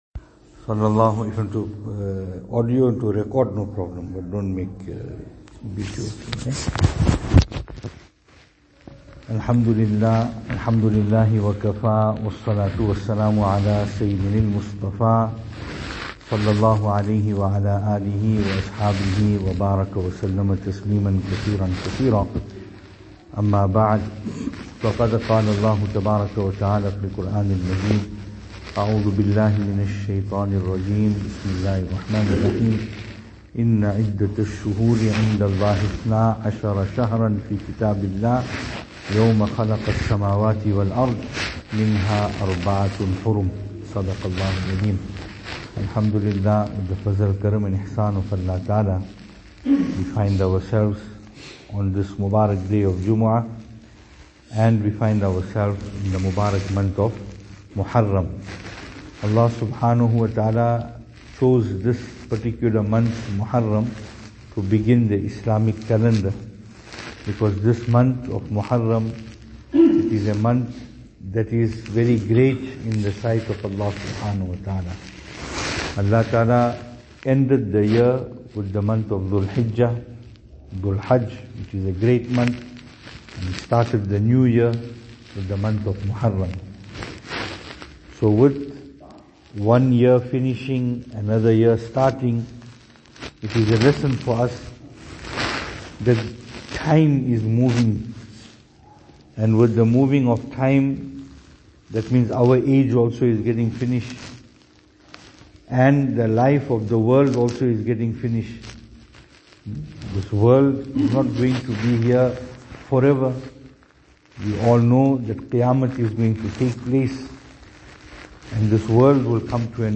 Jumu’ah Bayaan From Bhamshela